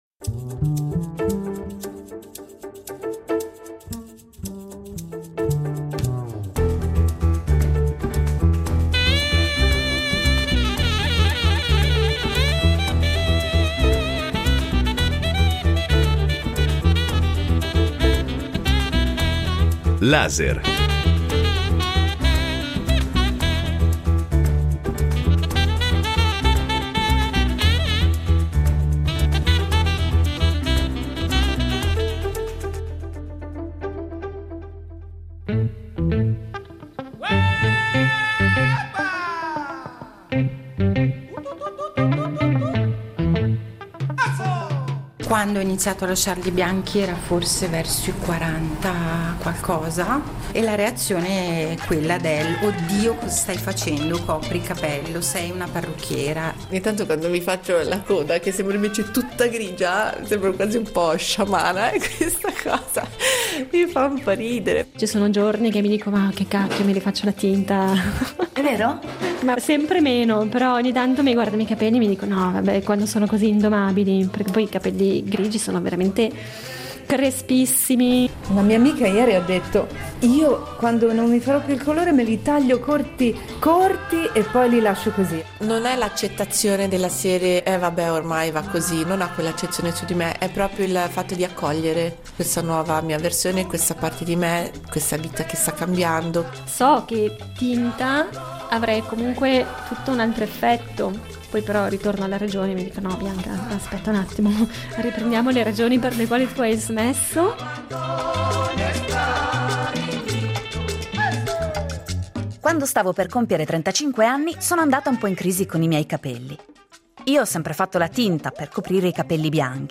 quattro donne della Svizzera italiana con i capelli grigi.